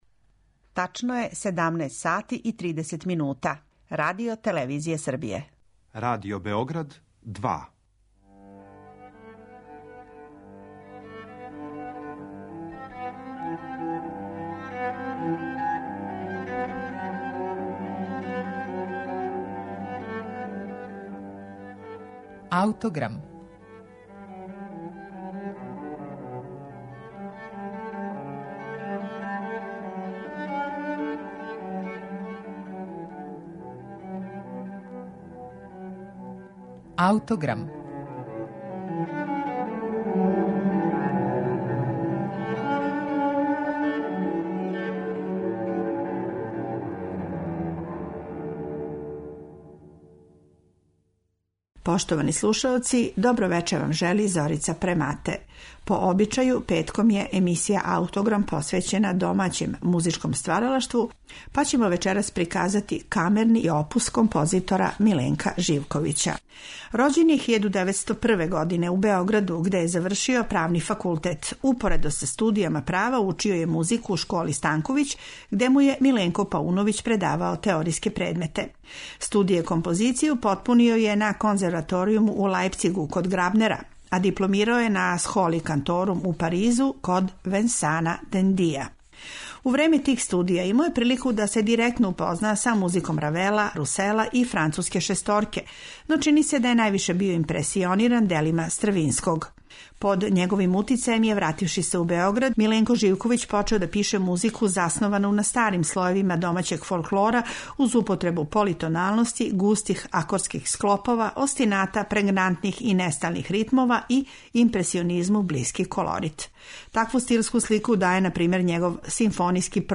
Овога пута емитоваћемо камерна дела нашег угледног композитора и професора Музичке академије из прошлог века, Миленка Живковића. Емисију ће започети „Свита у старом стилу" из његовог раног периода када је студирао у Паризу код Венсана Дендија, овога пута у каснијој верзији за флауту, гудачки квартет и контрабас.